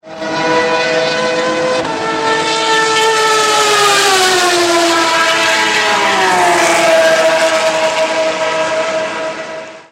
daytona24_rx_8.mp3